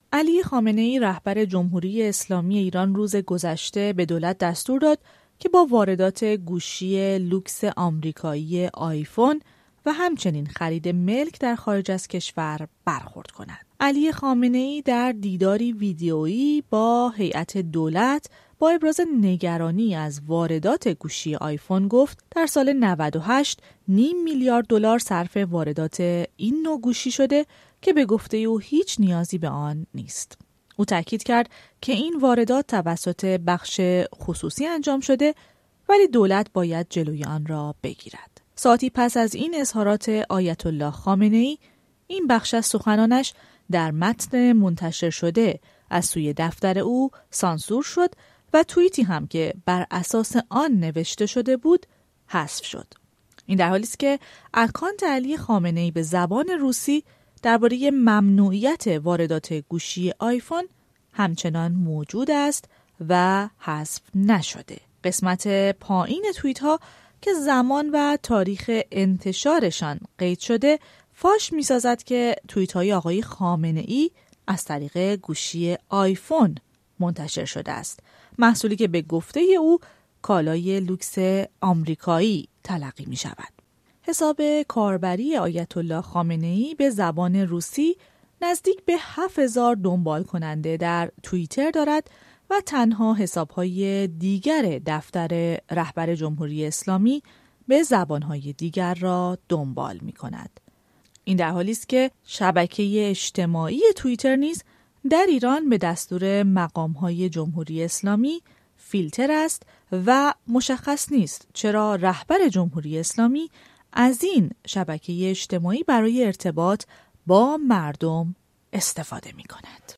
گزارش می دهد